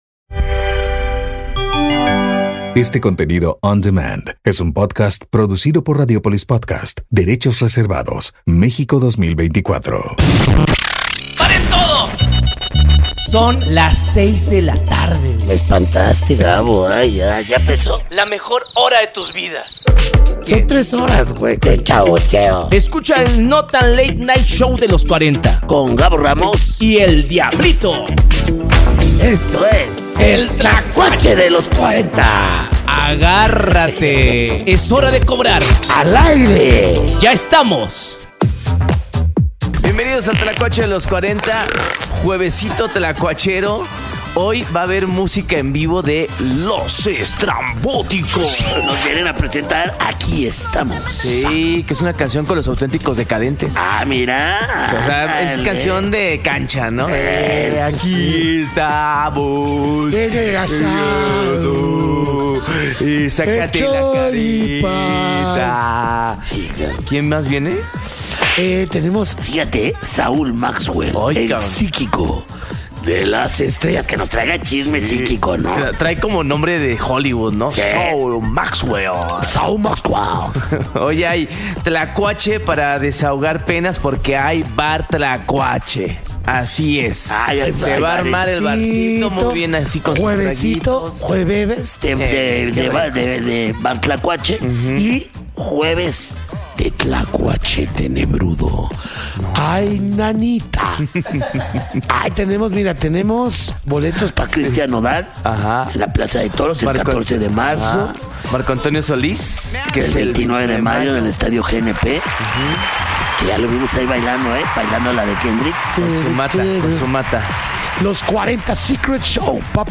Tambien estuvo con nosotros "Los Estrambóticos" tocando en vivo en la cabina Tlacuachera.